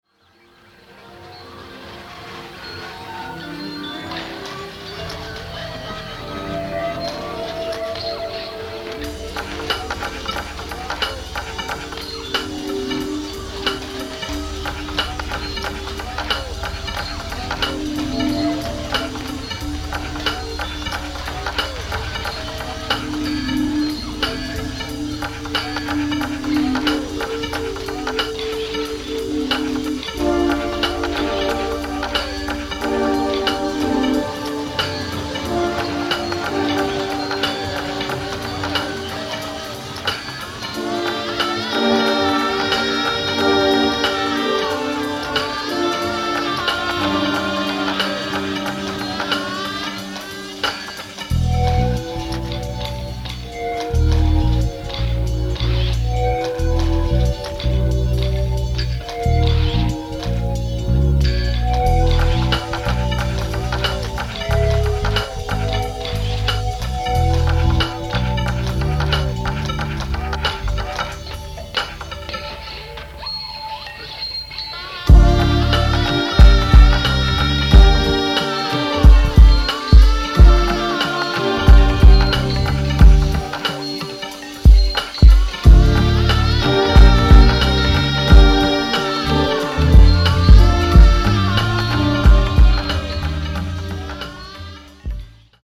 キーワード：ミニマル　サウンドスケープ　空想民俗